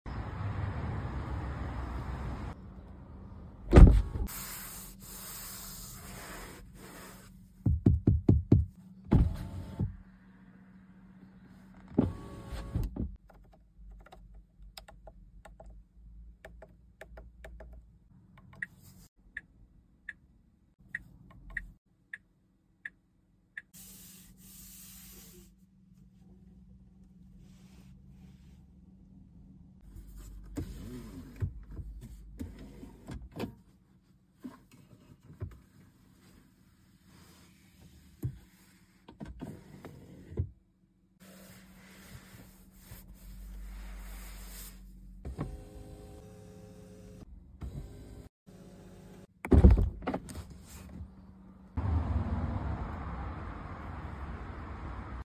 Tesla Model 3 2024 🔥 quality ASMR test 👌🏻⚡🖤